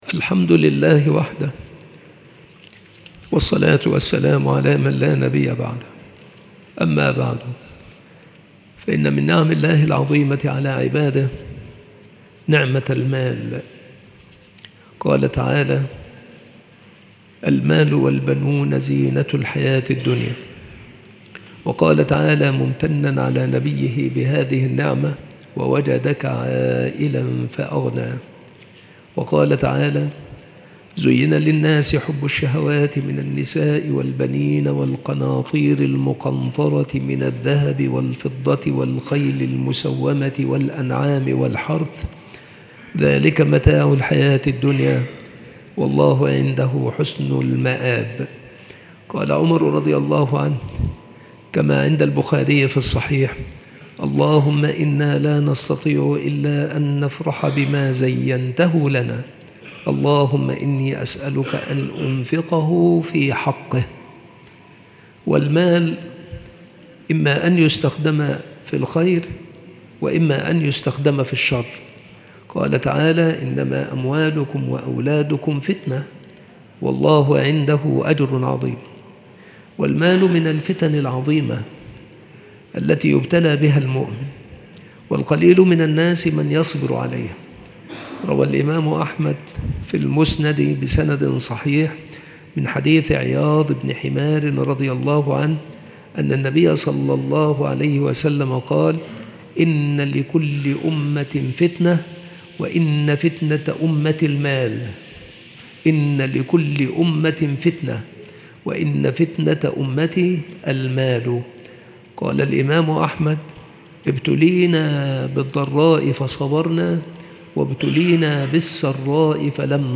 مواعظ وتذكير
مكان إلقاء هذه المحاضرة بالمسجد الشرقي - سبك الأحد - أشمون - محافظة المنوفية - مصر